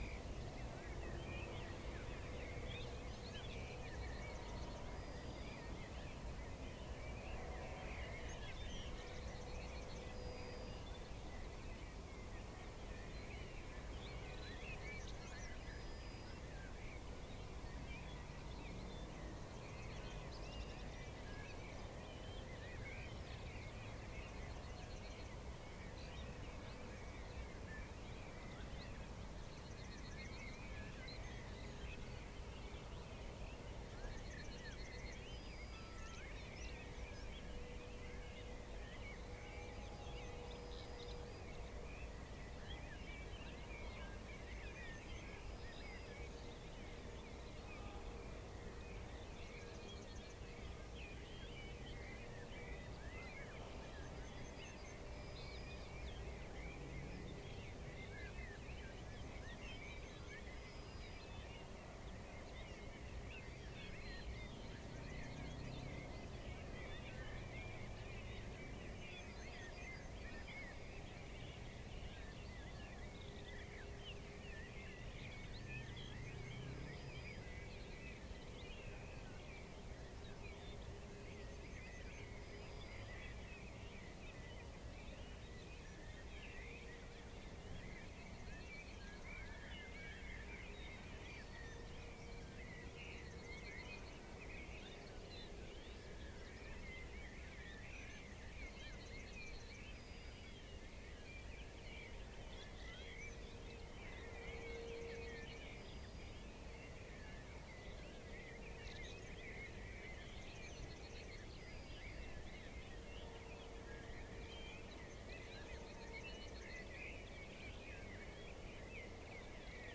Picus viridis
Turdus philomelos
Sylvia atricapilla
Troglodytes troglodytes
Fringilla coelebs